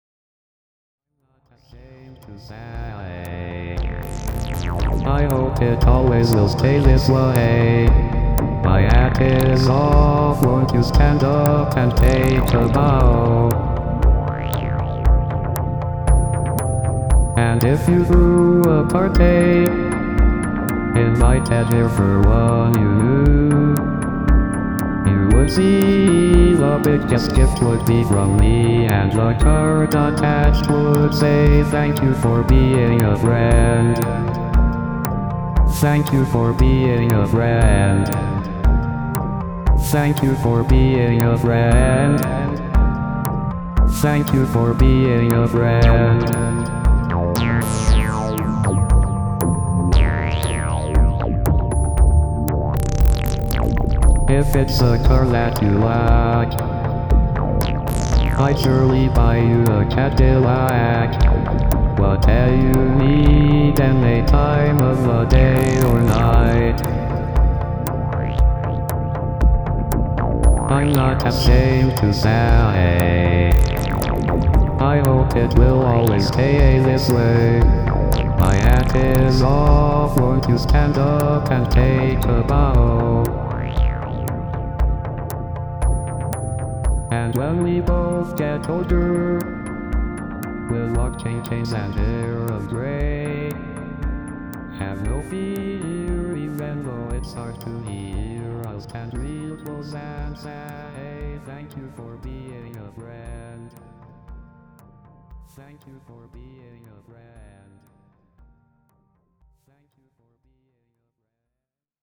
unusual squelchy synth sounds
computer vocals you'll ever hear.